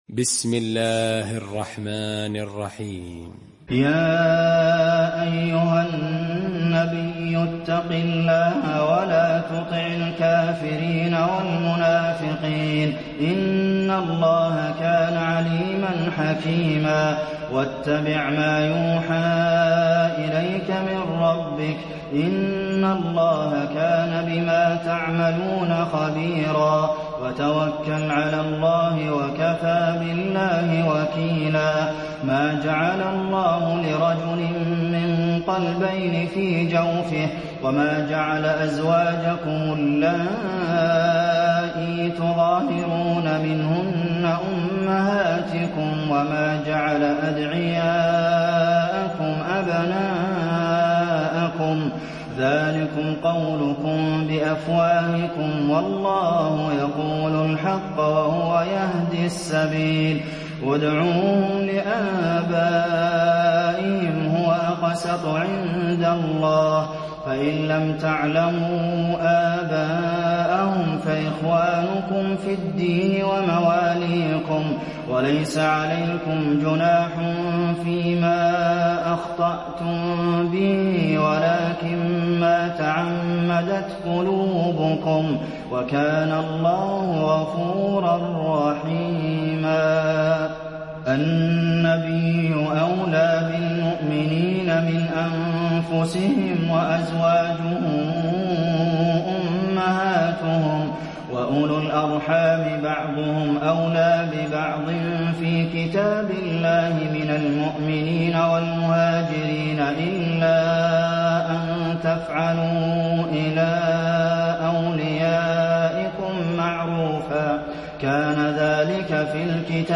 المكان: المسجد النبوي الأحزاب